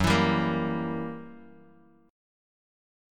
Gb7b9 Chord
Listen to Gb7b9 strummed